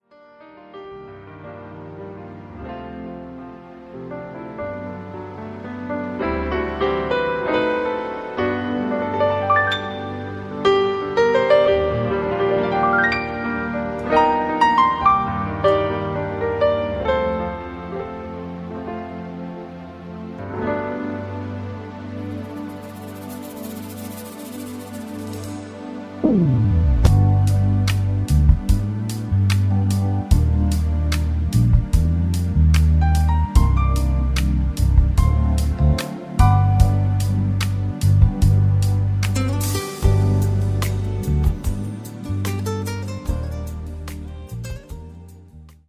pour saxophone